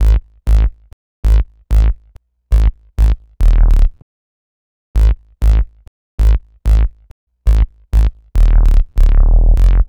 Bass 44.wav